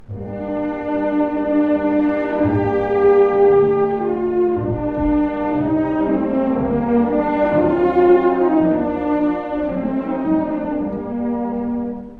↑古い録音のため聴きづらいかもしれません！（以下同様）
第1楽章は、ト短調で静かに始まります。
チェロと木管が、同じ音を繰り返すだけの導入。
たったそれだけなのに、どこか郷愁を誘う空気が漂います。